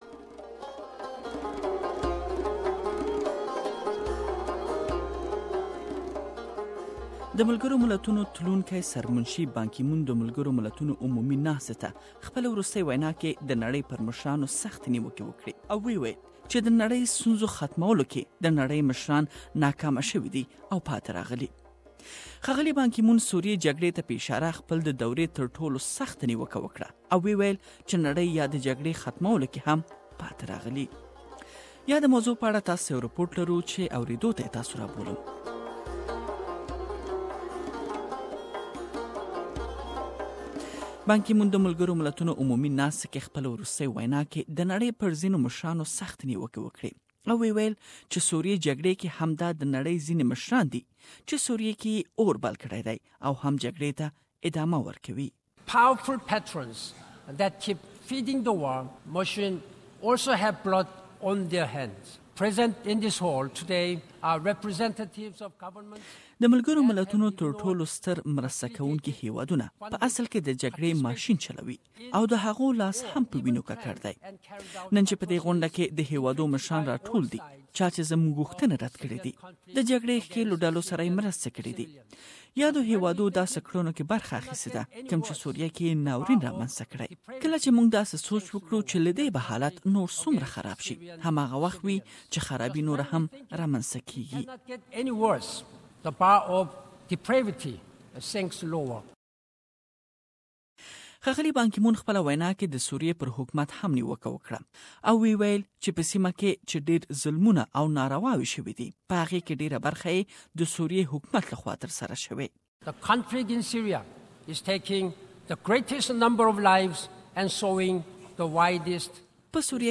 Departing UN Secretary General Ban Ki-moon has rebuked leaders at the United Nations General Assembly for failing to tackle many of the world's problems. Mr Ban singled out the ongoing conflict in Syria, offering some of the most stinging criticism of his tenure as head of the world body. Ban Ki-moon used his final address to the General Assembly to unleash his outrage at leaders who have been supporting the five-year-long conflict in Syria.